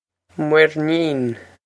muirnín mwer-neen
Pronunciation for how to say
This is an approximate phonetic pronunciation of the phrase.